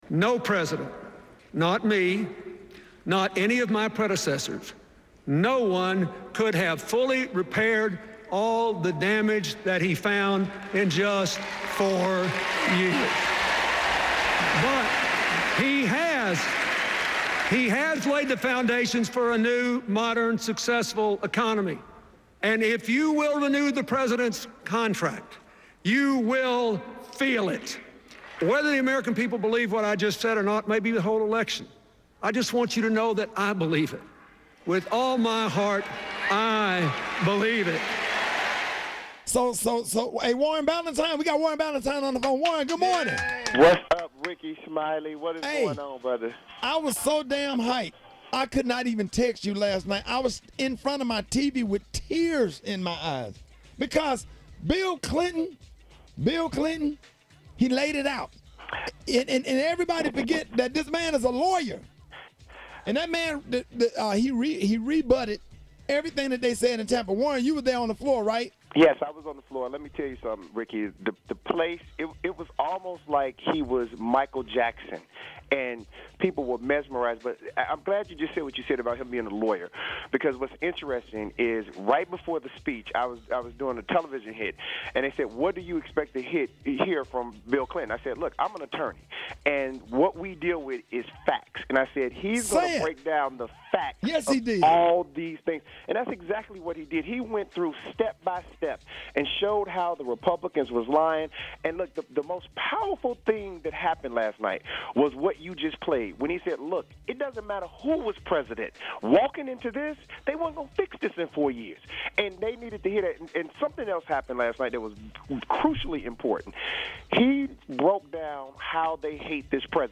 CLICK TO HEAR PRESIDENT BILL CLINTON TELL THE TRUTH AT THE DNC!
president-bill-clinton-tell-the-truth-at-the-dnc.mp3